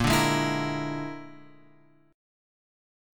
A#7b5 chord {6 5 6 x 5 6} chord